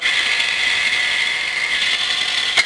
Mars Microphone: Test Sound Data (Robot Arm, 20 kHz)